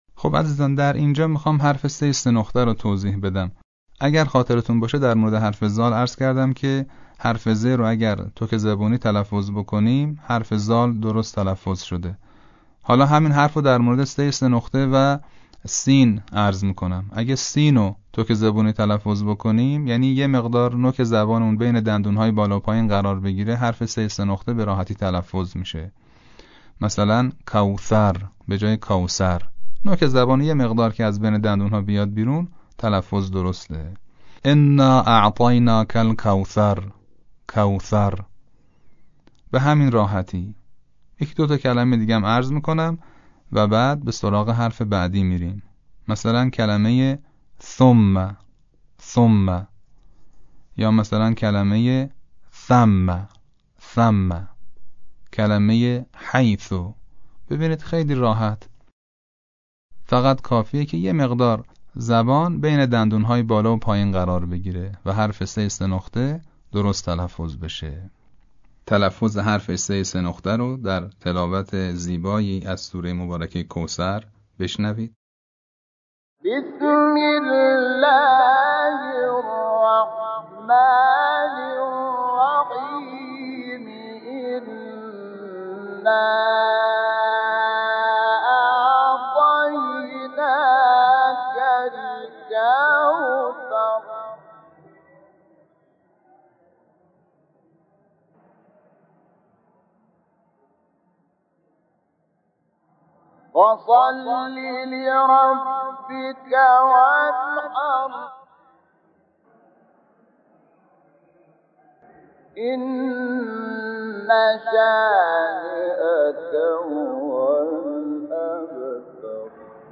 اما در تلاوت فصیح عربی قرآن، از مماس شدن نوک زبان با لبه ی دندان های ثنایای بالا (دندانهای خرگوشی) همراه با خروج هوا، ایجاد می گردند.
مثال های صوتی تلفظ حروف ذال و ثاء